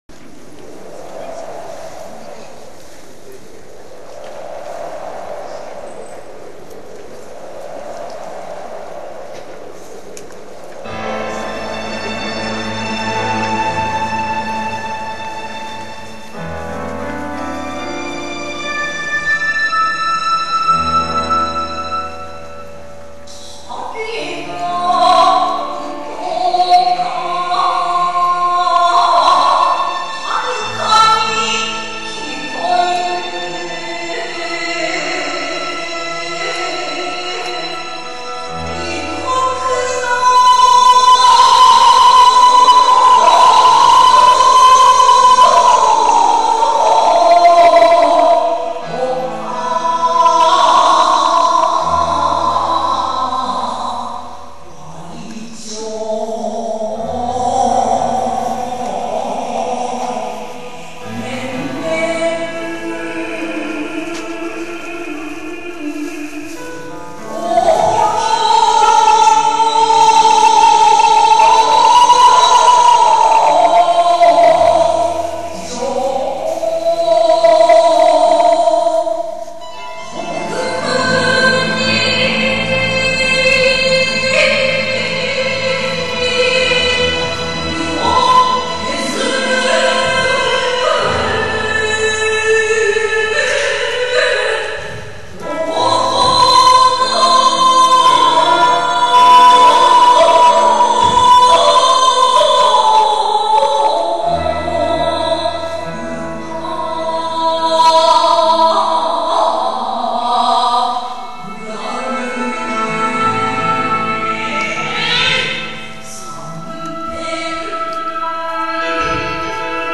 ジ ョ イ ン ト リ サ イ タ ル
平成十九年八月二十六日 (日)　於：尼崎アルカイックホール
歌：二部男性